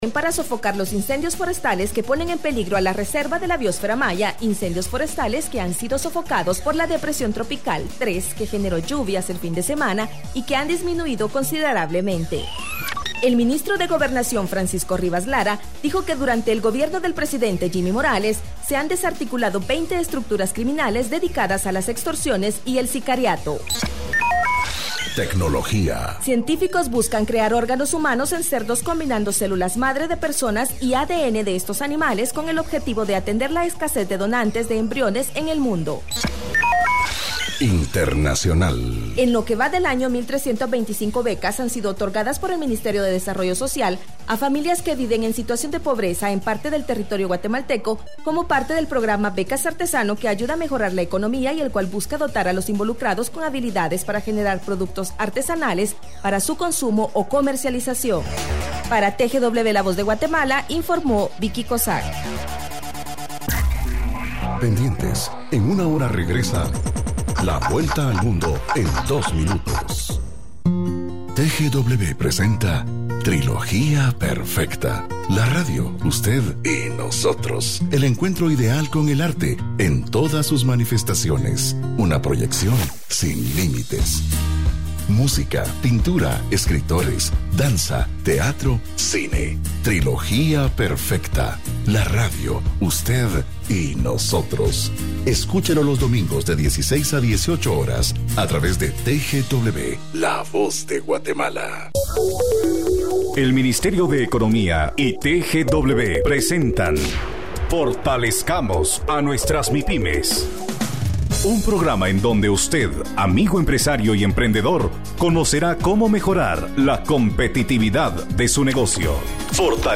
Progra ma de radio Fortalezcamos a Nuestras Mipyme TGW 107.3 FM